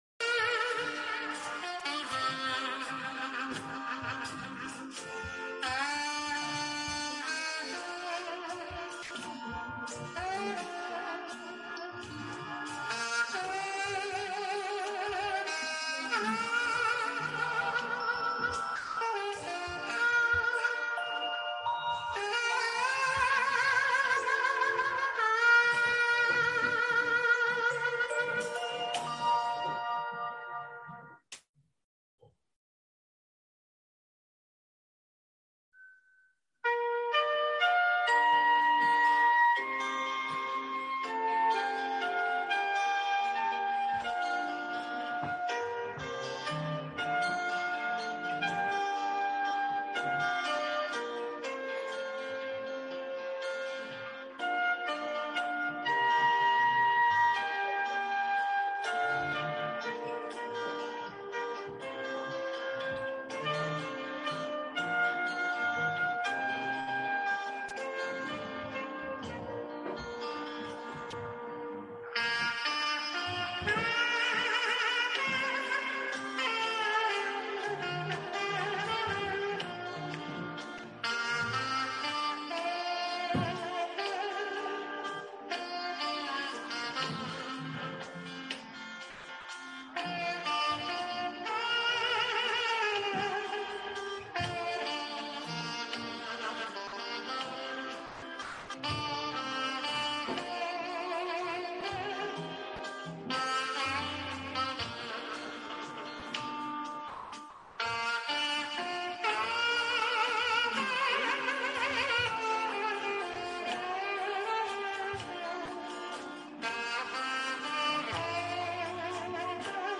The Greatest Pleasure (Youth Meeting Avoca)) Church Services